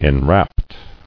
[en·rapt]